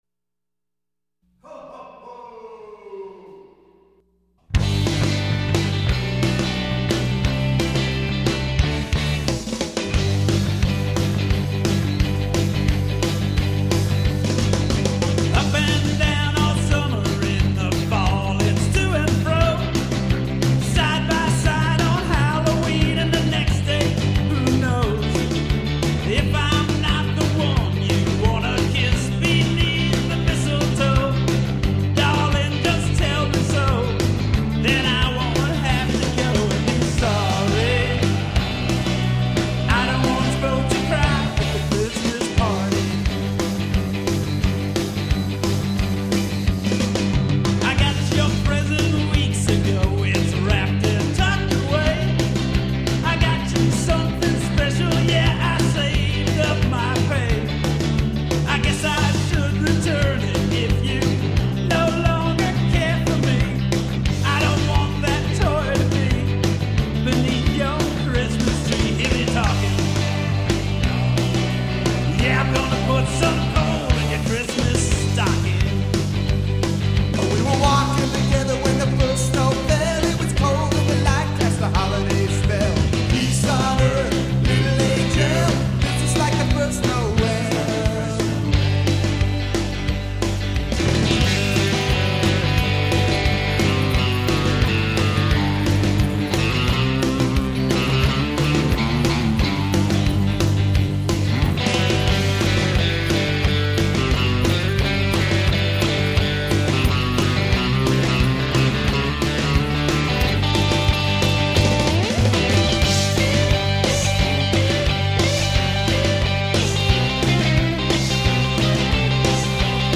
studio recordings